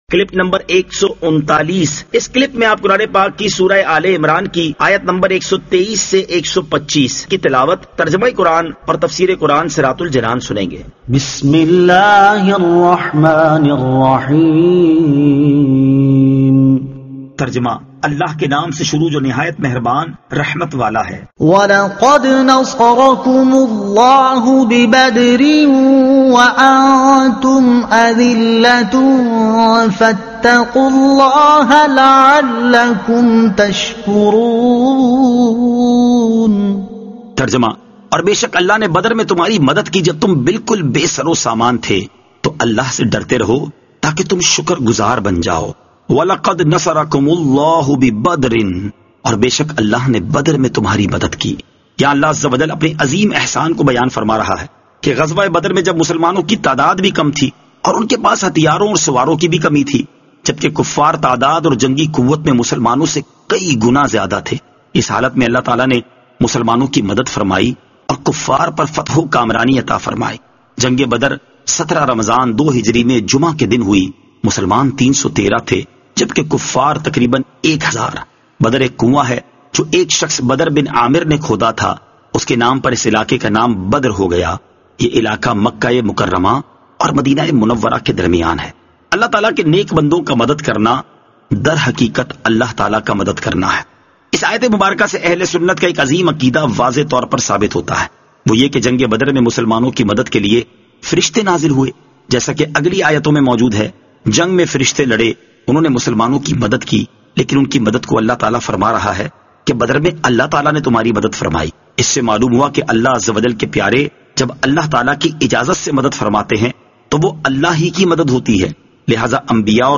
Surah Aal-e-Imran Ayat 123 To 125 Tilawat , Tarjuma , Tafseer